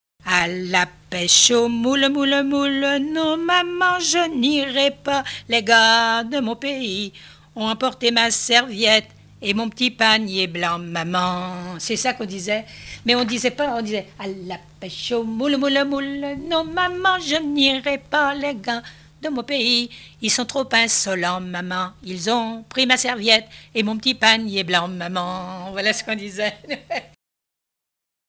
Couplets à danser
Enquête Arexcpo en Vendée
Pièce musicale inédite